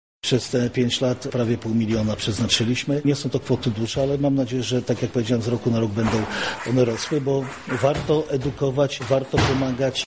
„Jest to zatrważająca liczba. W przyszłości będziemy zwiększać środki na profilaktykę.”- mówi Marszałek Województwa Lubelskiego Jarosław Stawiarski: